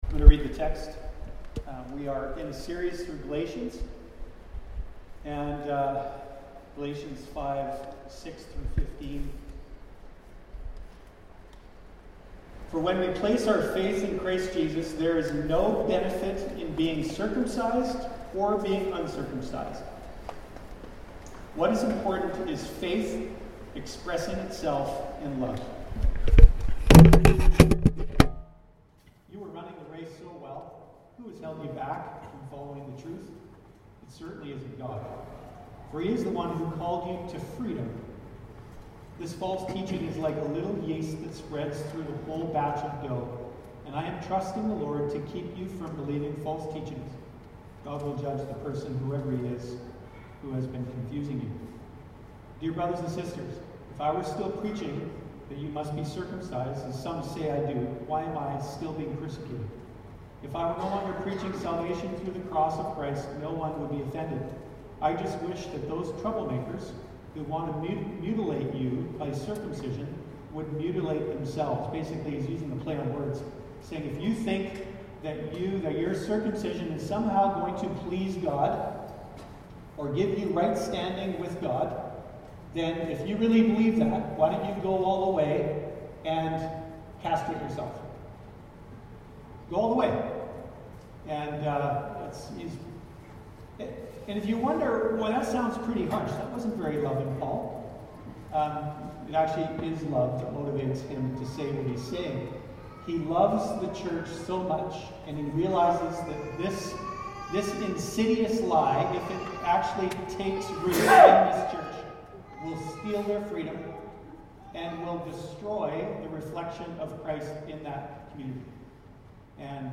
Sermons | The River Church